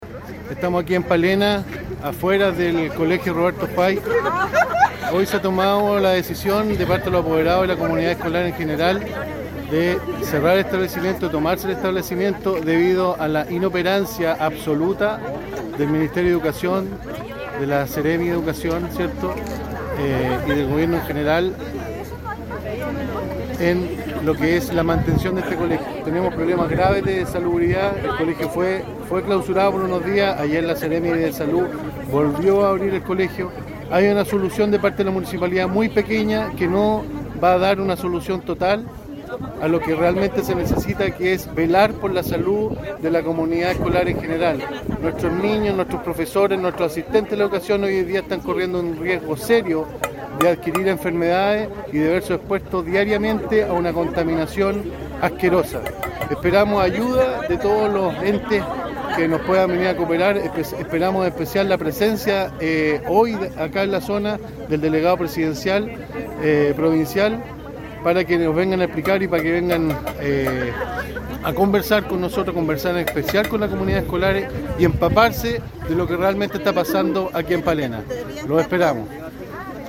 La toma de la escuela Roberto White comenzó a primera hora de esta mañana de jueves 15 de mayo, y se espera por los participantes que las autoridades lleguen con soluciones reales durante esta jornada, como lo explicó el concejal de la comuna de Palena, Ricardo Carrasco.
15-CONCEJAL-PALENA-RICARDO-CARRASCO-TOMA-ESCUELA.mp3